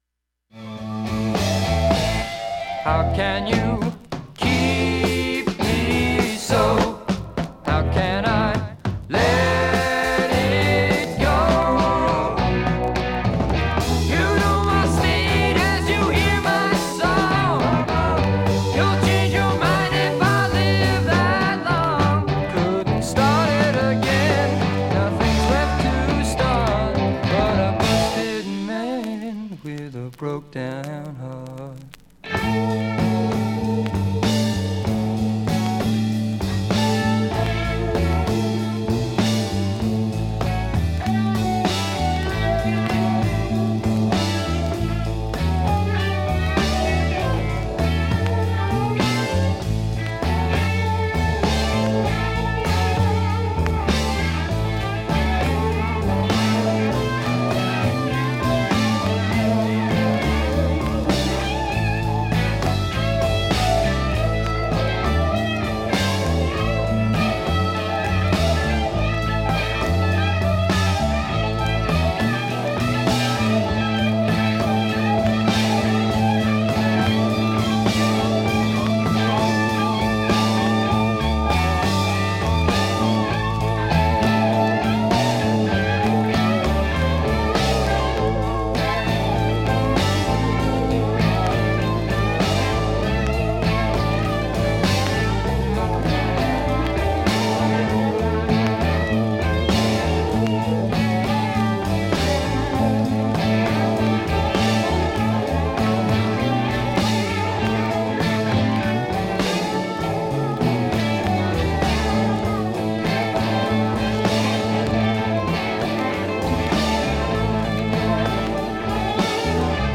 音質目安にどうぞ